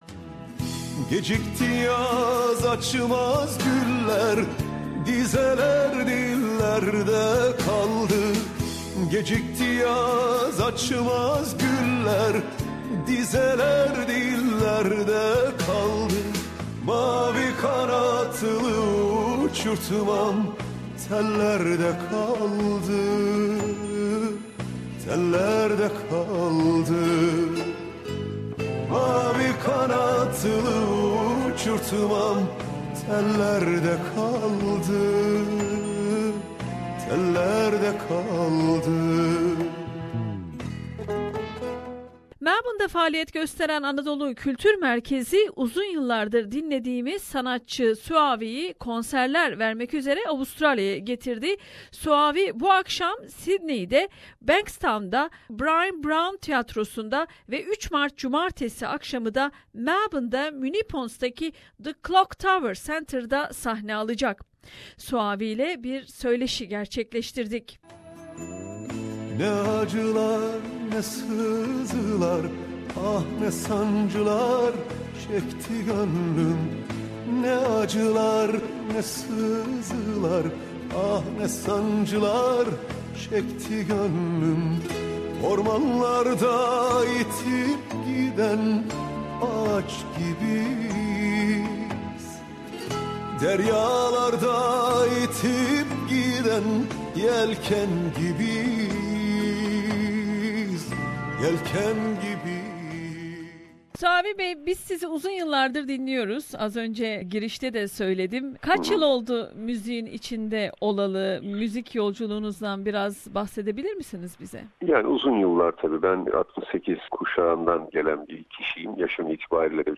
Sydney ve Melbourne'de konserler vermek üzere Avustralya'ya gelen Suavi ile söyleşi yaptık.